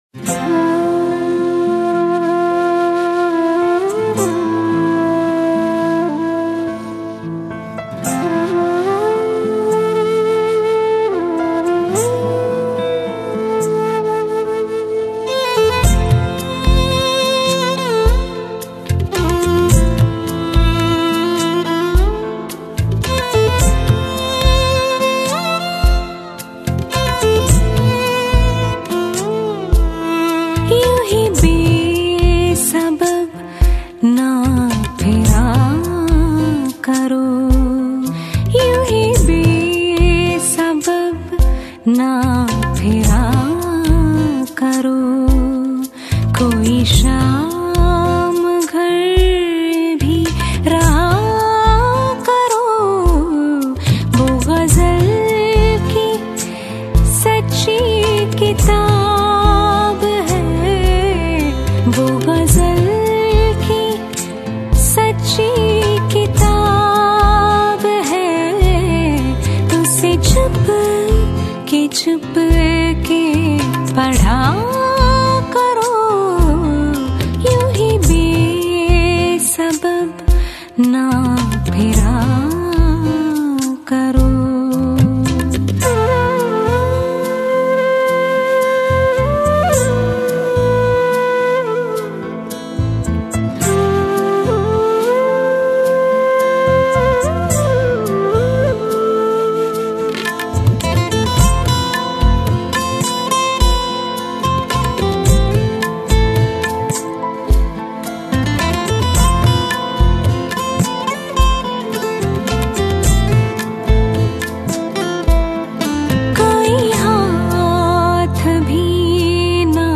Ghazals